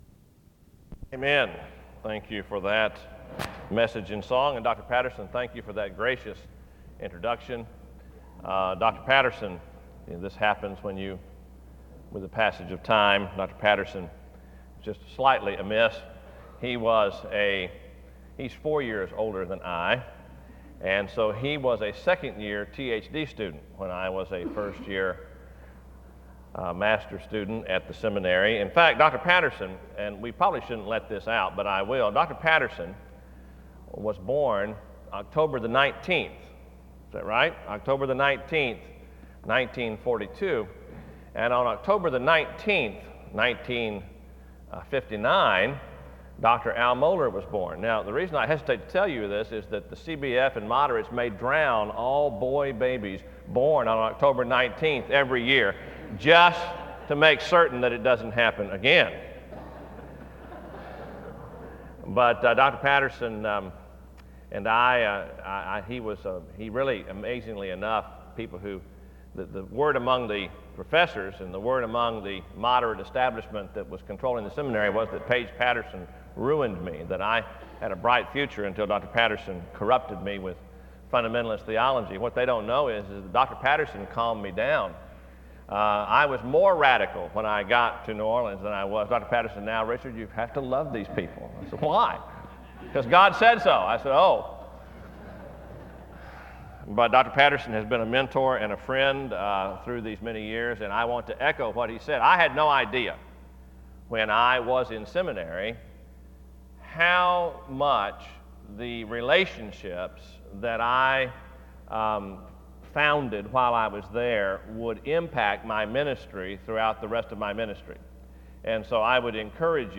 Home SEBTS Chapel - Richard Land...